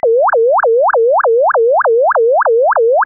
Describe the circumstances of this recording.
(Fig. 5f): NOTAP result using Zero Order Hold interpolation, an oversampling ratio, N = 50, and the receiver sampling frequency of 4 kHz.